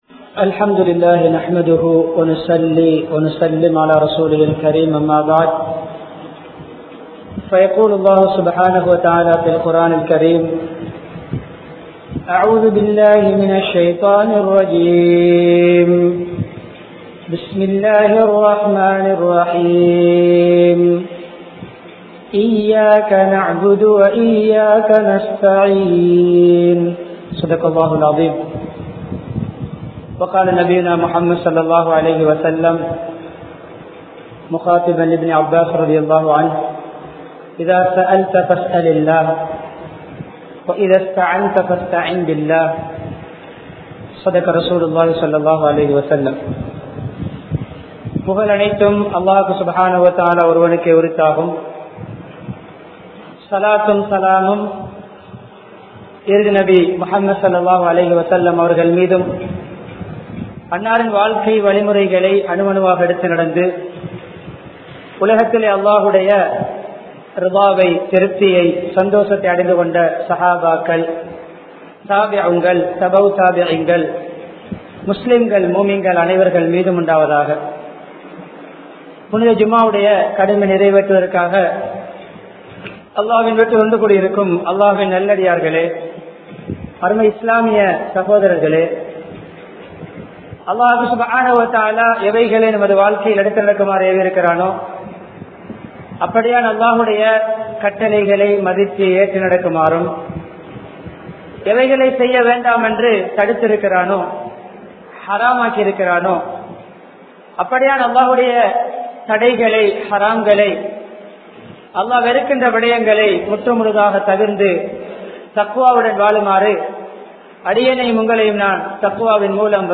Allahvidam Maathiram Uthavi Theduvom(அல்லாஹ்விடம் மாத்திரம் உதவி தேடுவோம்) | Audio Bayans | All Ceylon Muslim Youth Community | Addalaichenai
Colombo 15, Mattakuliya, Mutwal Jumua Masjidh